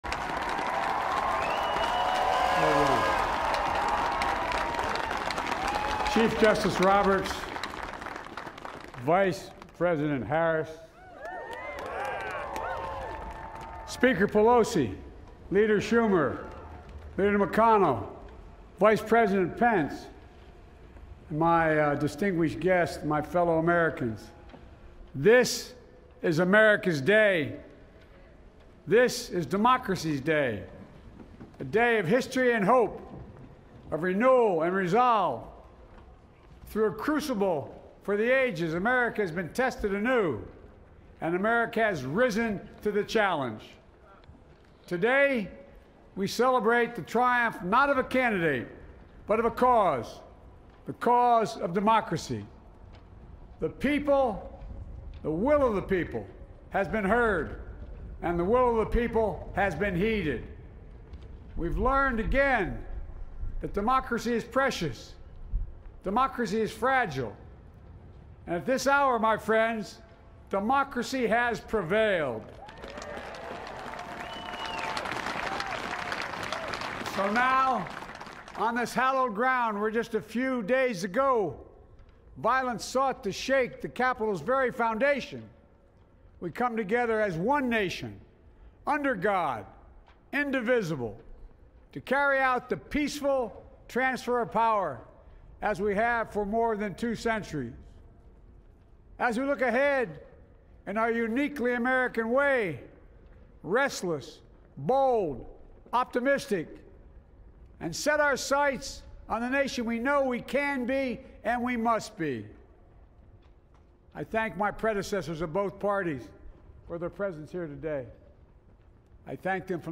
LISTEN: Joe Biden gives first speech as president